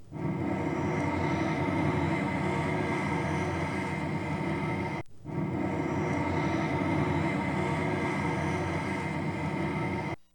Source: Bowing on bridge (0:00 - 5:00)
Bowing_Bridge.aiff